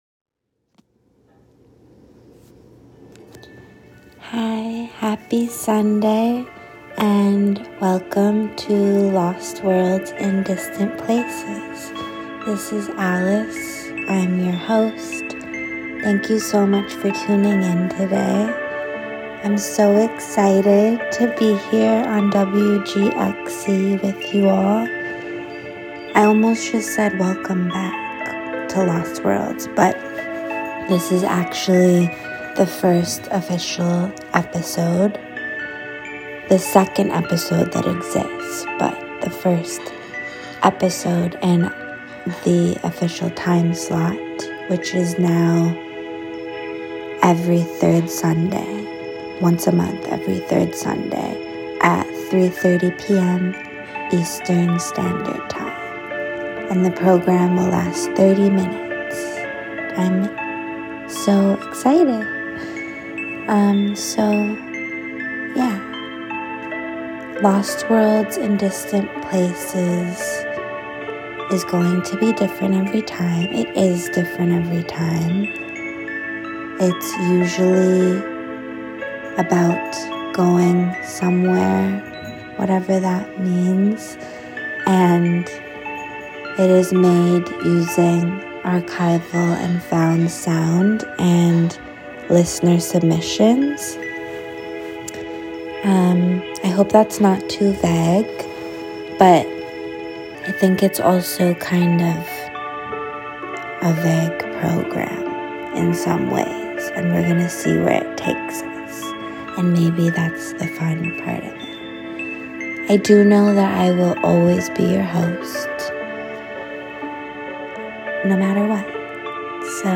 Sounds from inside an elevator.